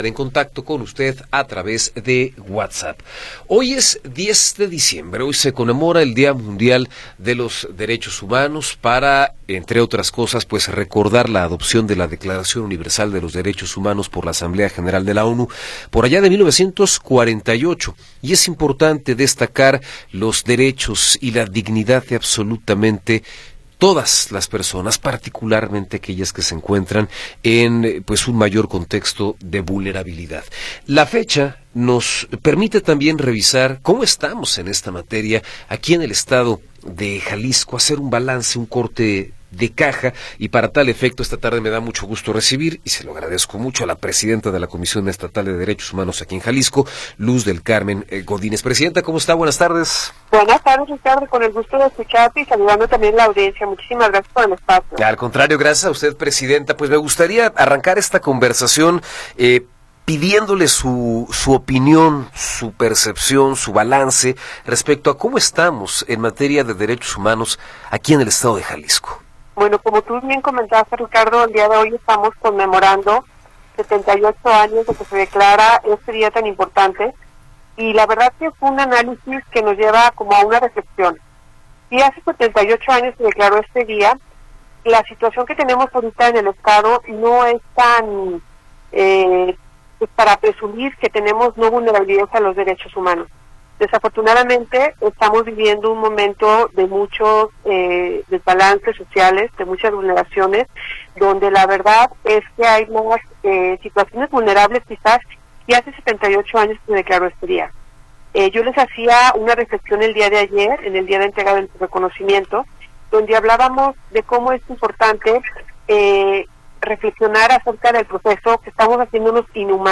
Entrevista con Luz del Carmen Godínez González